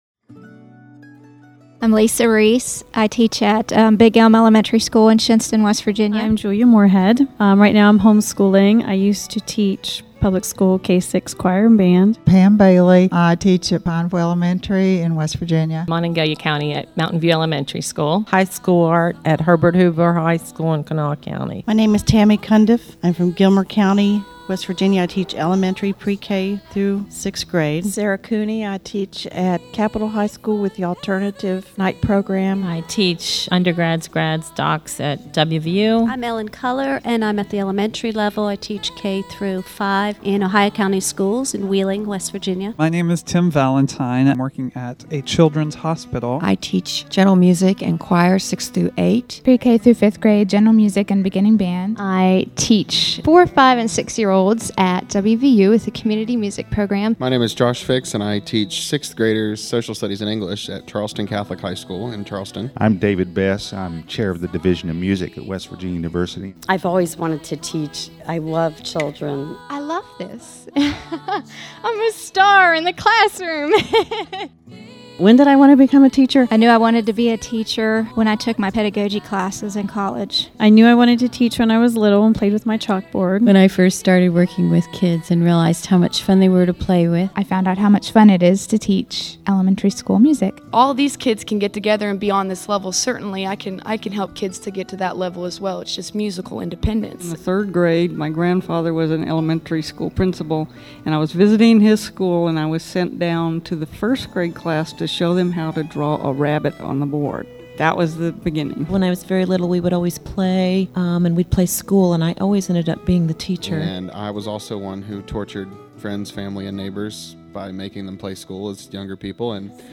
Thirty-five teachers…thirty-five perspectives on career path, challenges and special moments. This 6 minute montage celebrates the views of participants in West Virginia University’s Summer Instritute for Teaching Excellence in the Arts, Children’s Museum of Pittsburgh, June 21 2006